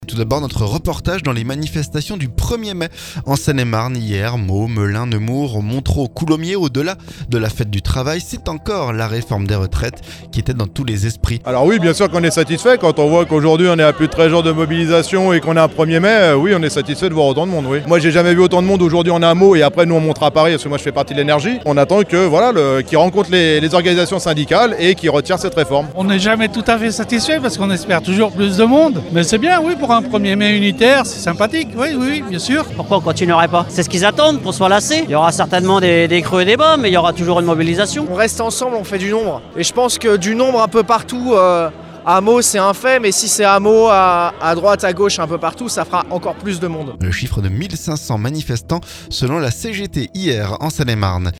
Ce mardi reportage dans les manifestations du 1er mai en Seine-et-Marne.